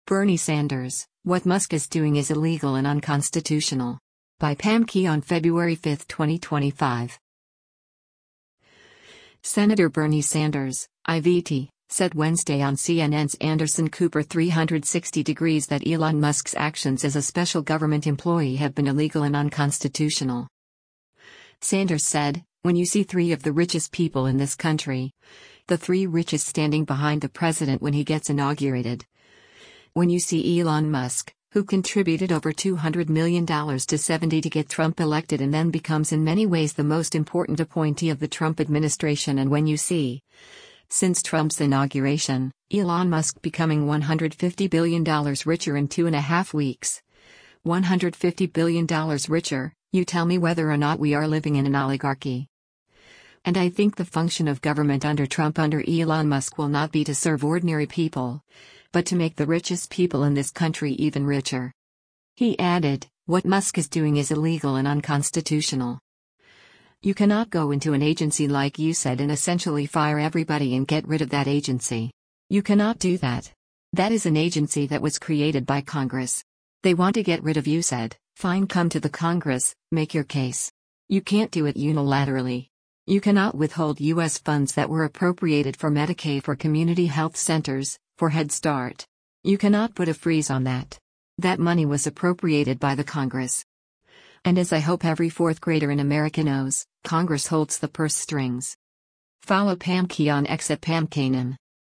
Senator Bernie Sanders (I-VT) said Wednesday on CNN’s “Anderson Cooper 360°” that Elon Musk’s actions as a special Government employee have been illegal and unconstitutional.